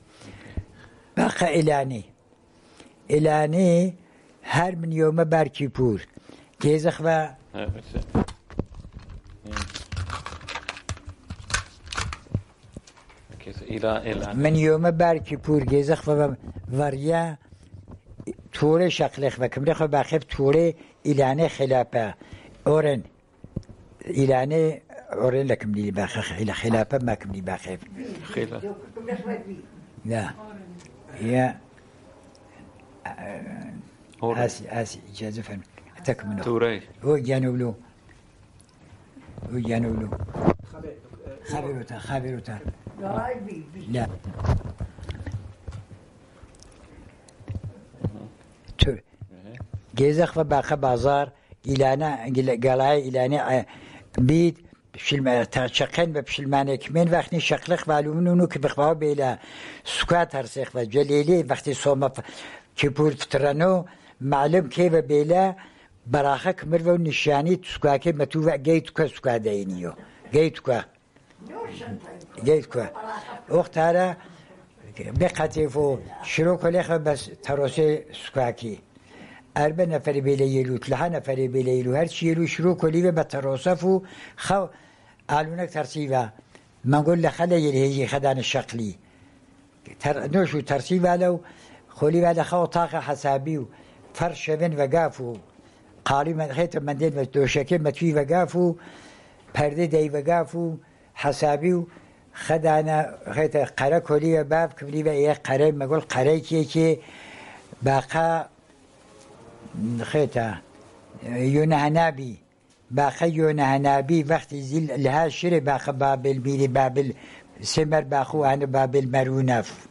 Sanandaj, Jewish: A Wedding in Sanandaj